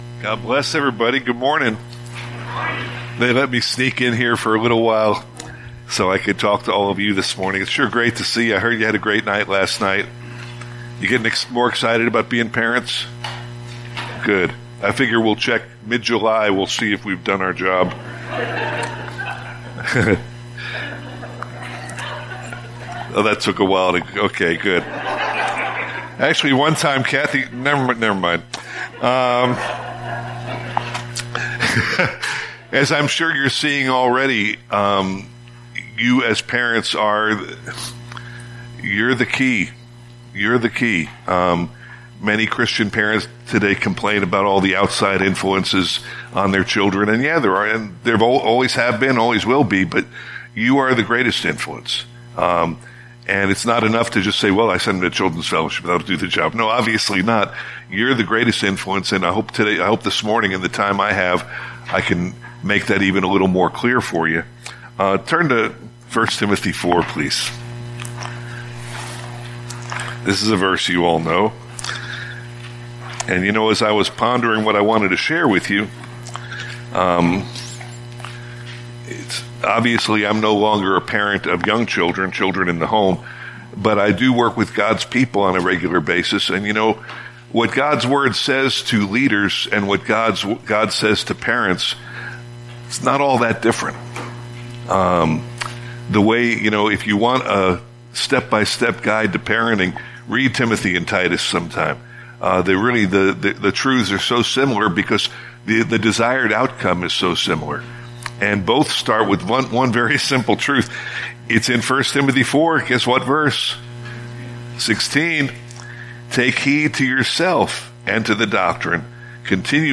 Part 2 of 5 in a teaching series on Christian parenting.